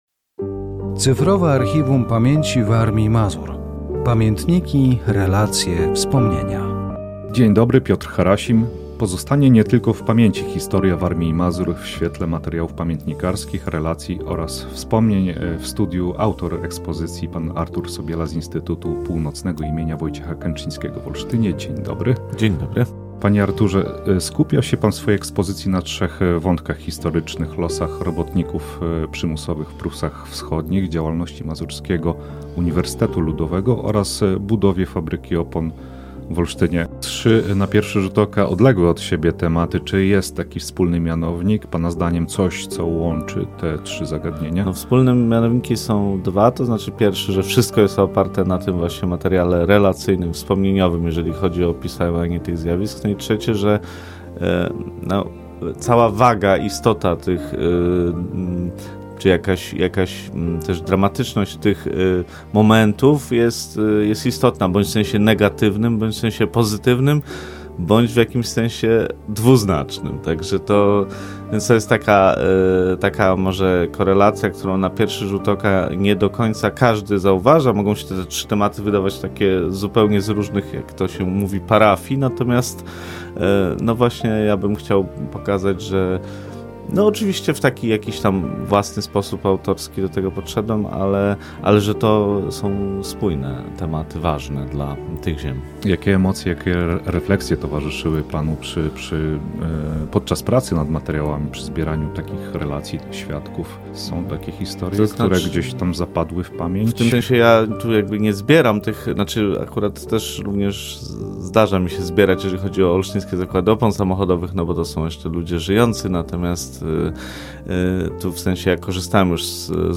Audycja radiowa dotycząca projektu "Cyfrowe Archiwum Pamięci Warmii i Mazur. Pamiętniki, relacje, wspomnienia"
Audycja radiowa poświęcona projektowi "Cyfrowe Archiwum Pamięci Warmii i Mazur. Pamiętniki, relacje, wspomnienia".